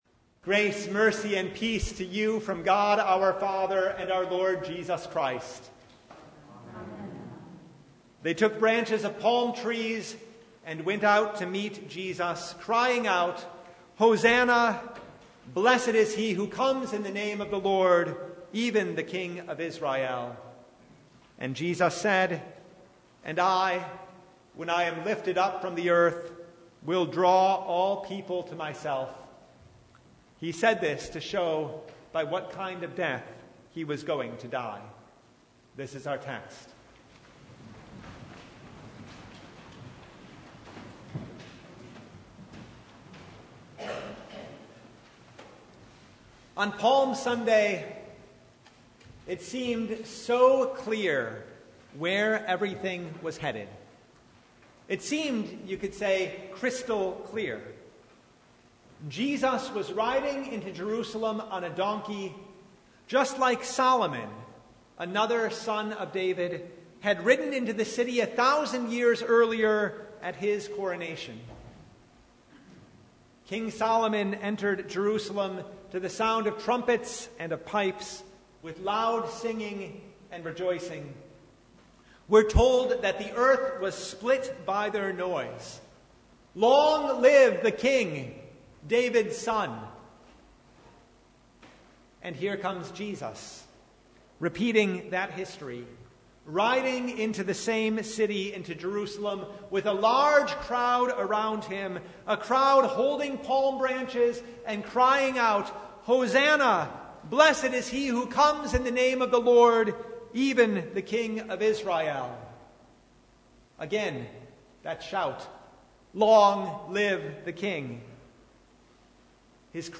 Service Type: Palm Sunday